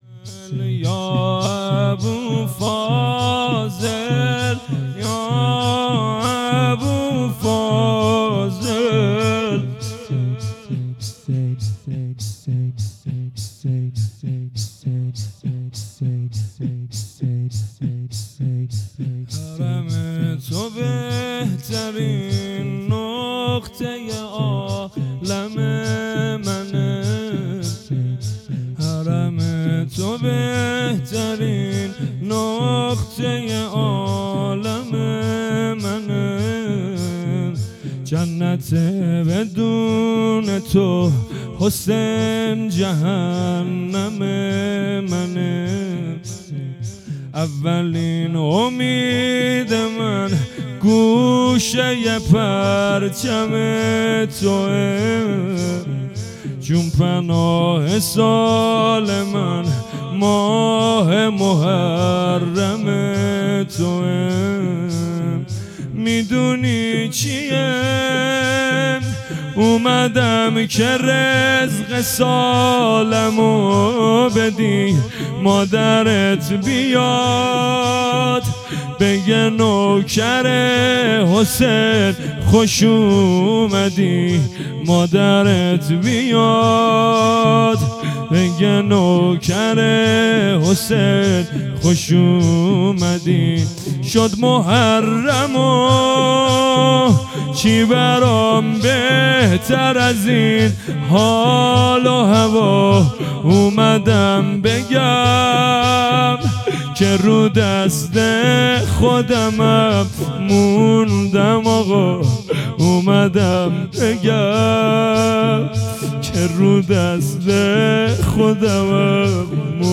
هیئت مکتب العباس(ع)
0 0 زمینه | حرم تو بهترین نقطه ی عالم منه